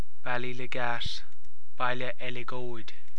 Comhad Fuaime Foghraíochta